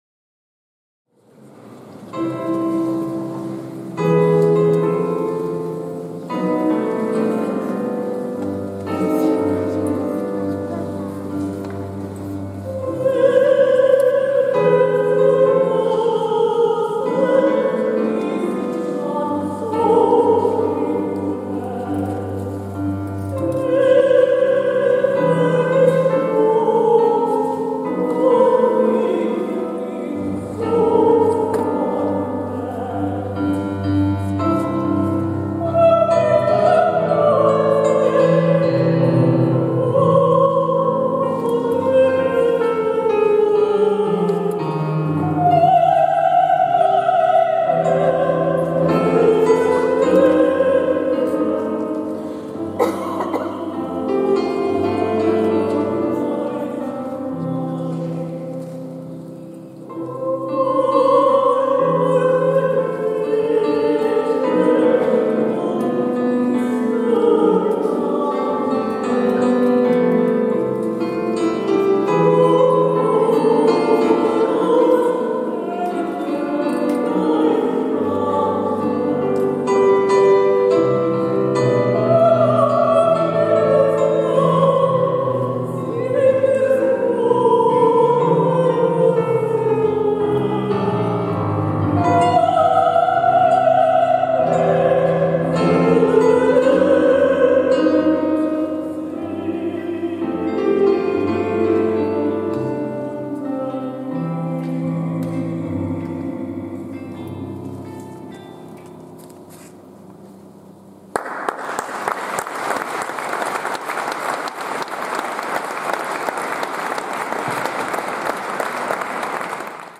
Actuació musical: Nachspiel, F. Nietzsche. 7 Lieder Nº6
Actuacions musicals
a l’Aula Magna Modest Prats de l'edifici de Sant Domènec, al campus de Barri Vell de la Universitat de Girona